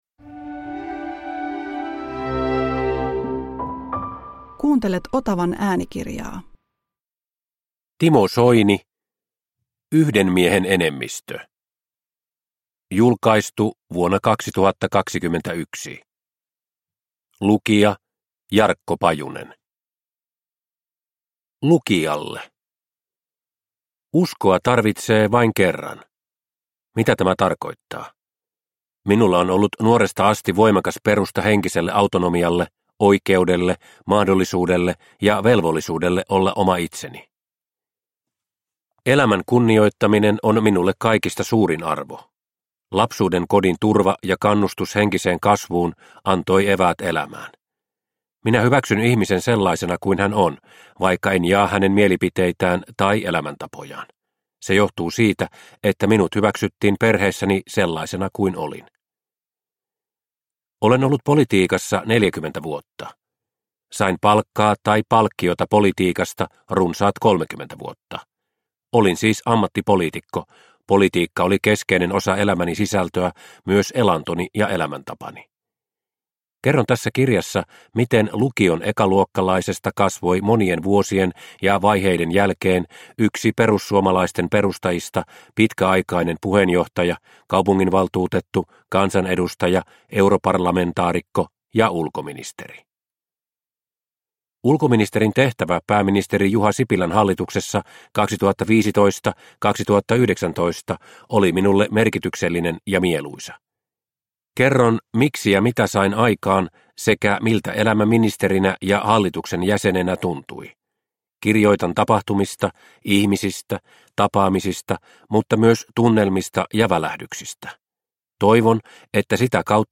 Yhden miehen enemmistö – Ljudbok